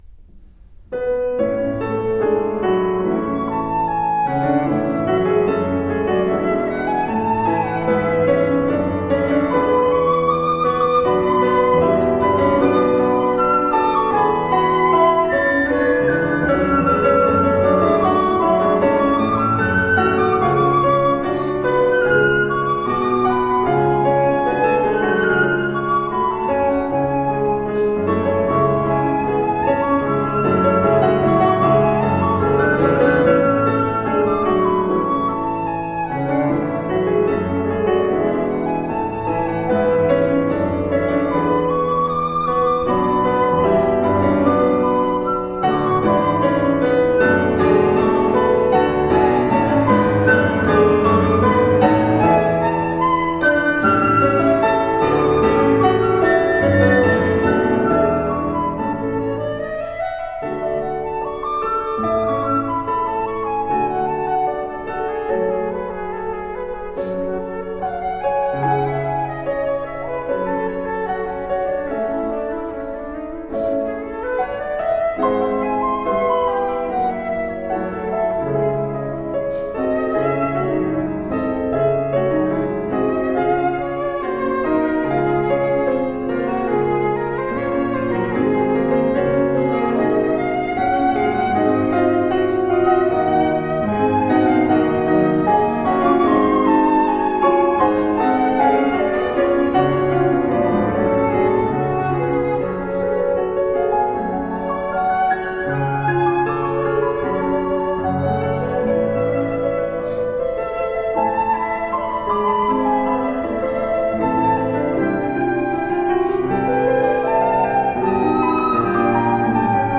Tagged as: Classical, Instrumental Classical, Flute